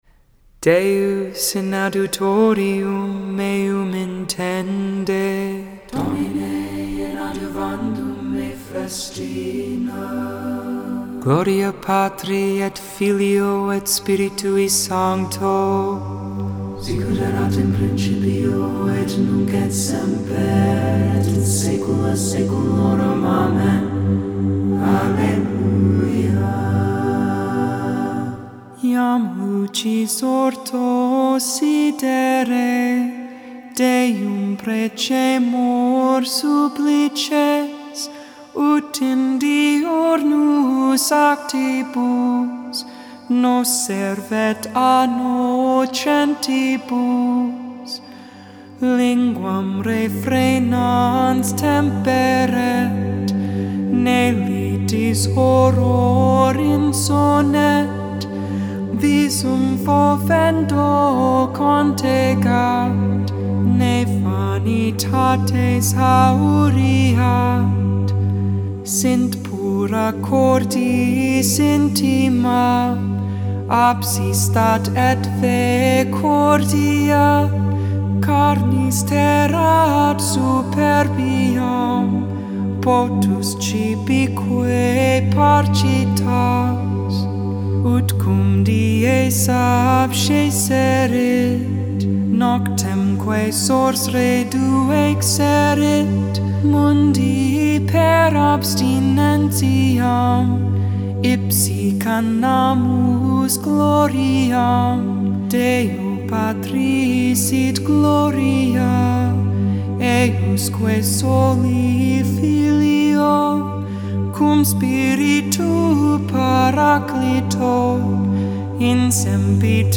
2.4.21 Lauds (Thurs Morning Prayer)